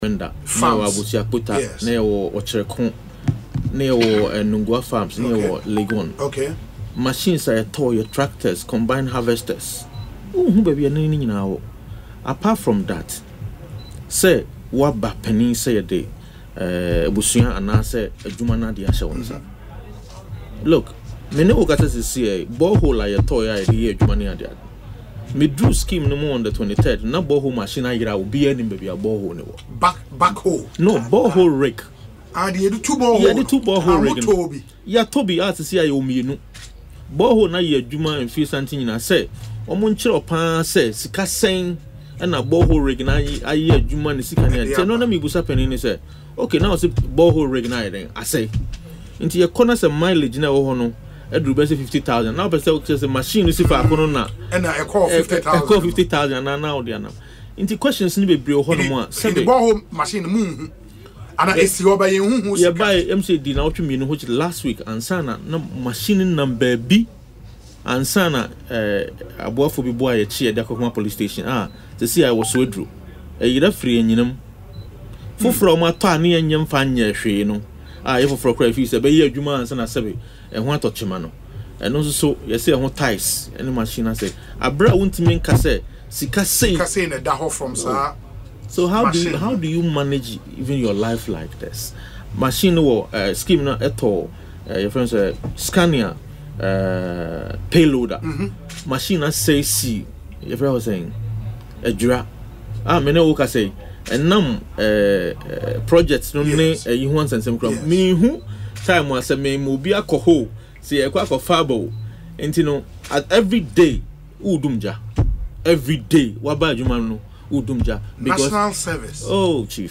Speaking in an interview on Asempa FM’s Ekosii Sen, Mr. Gyamfi stated that the missing borehole machine is not an isolated case, as several other machines, including tractors and combine harvesters, remain unaccounted for.